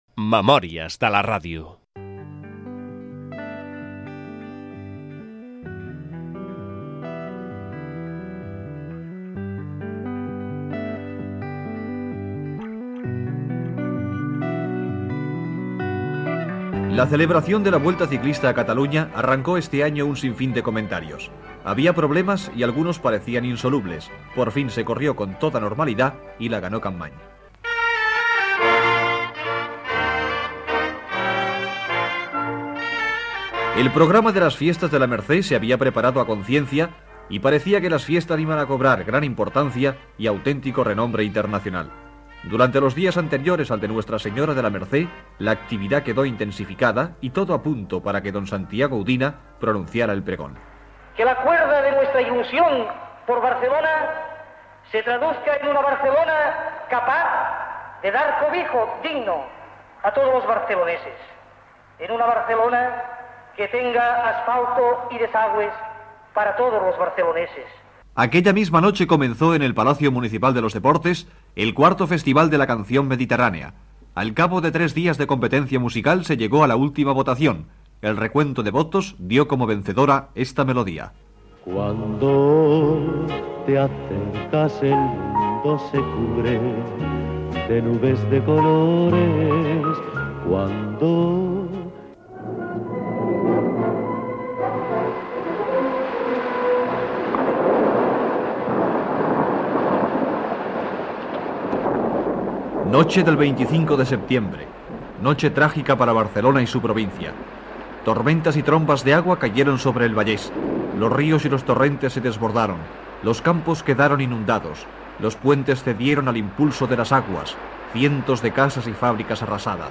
Indicatiu del programa
resum d'esdeveniments significatius de 1962 Gènere radiofònic Divulgació